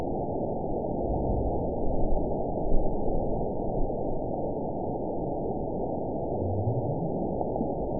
event 920461 date 03/26/24 time 20:08:51 GMT (1 year, 1 month ago) score 9.25 location TSS-AB02 detected by nrw target species NRW annotations +NRW Spectrogram: Frequency (kHz) vs. Time (s) audio not available .wav